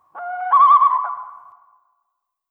dash-sounds-raw.wav